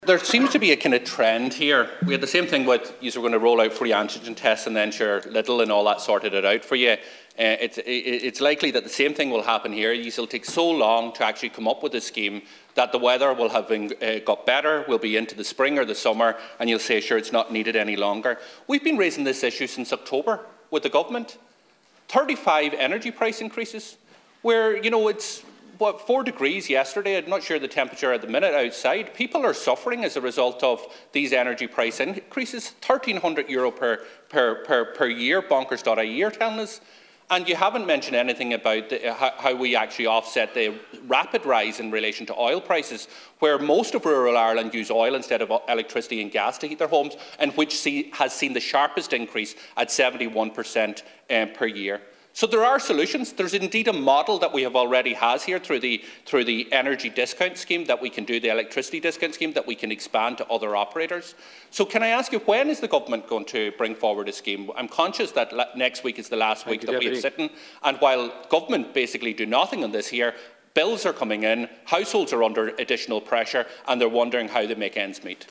Speaking in the Dail Deputy Pearse Doherty says by the time the Government actually come up with a solution it’ll be summertime again: